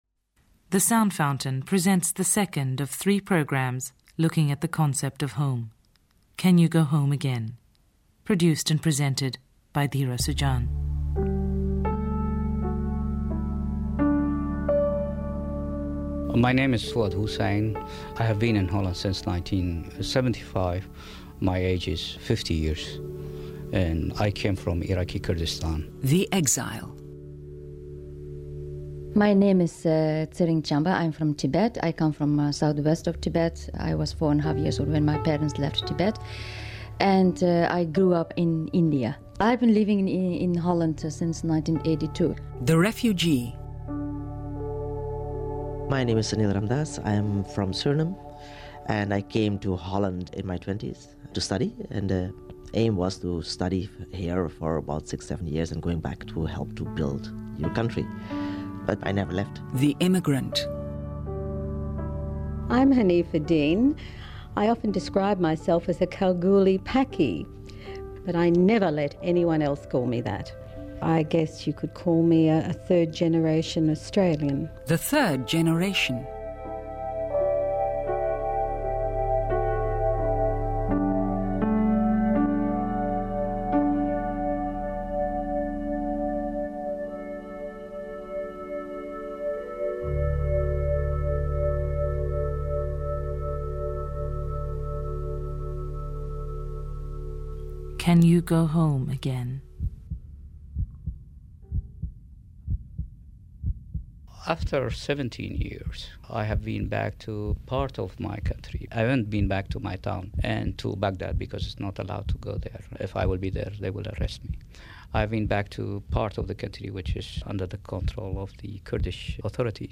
Our guests in this programme are from many different backgrounds and countries: Iraq, Tibet, India, Pakistan, Australia and Surinam. They all live in the Netherlands, and they have different answers to the question of whether they can go home again, speaking from their own perspective as: an exile, a refugee, an immigrant and a member of the third generation.